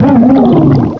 cry_not_jellicent.aif